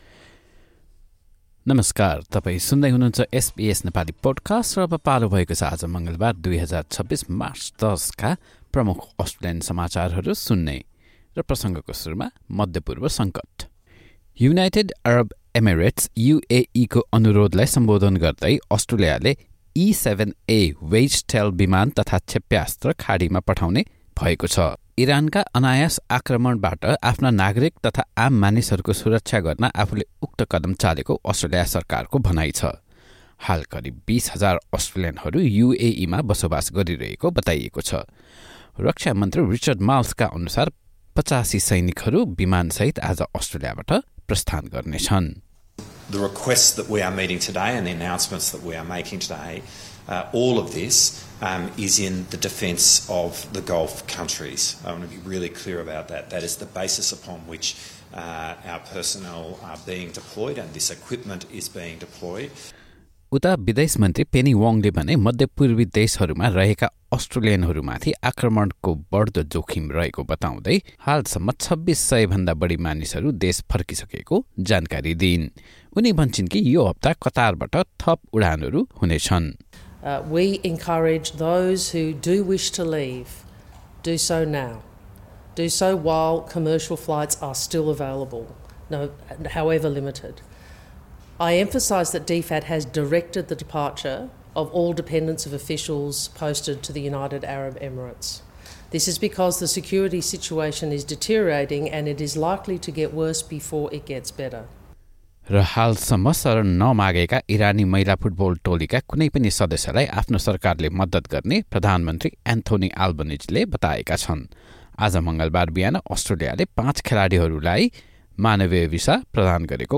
SBS Nepali Australian News Headlines: Tuesday, 10 March 2026